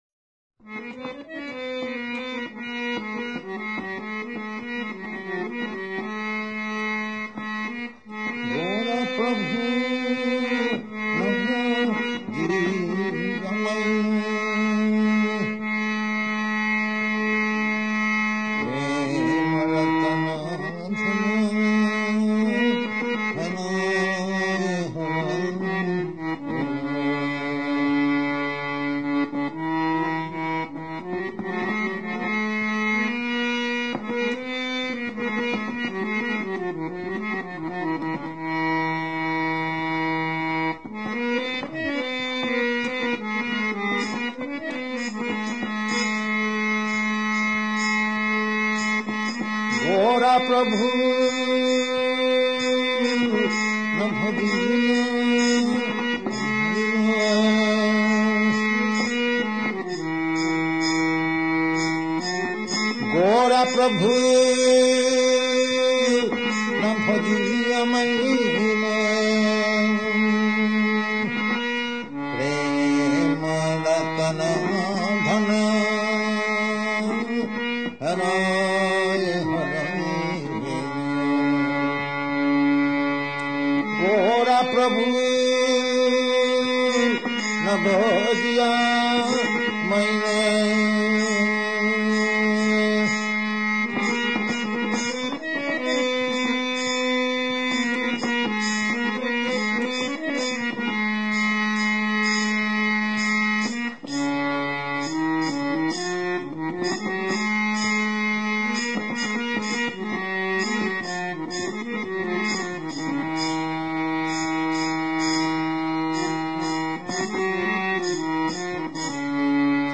690110 – Bhajan and Purport to Gaura Pahu – Los Angeles